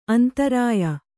♪ antarāya